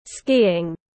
Môn trượt tuyết tiếng anh gọi là skiing, phiên âm tiếng anh đọc là /ˈskiː.ɪŋ/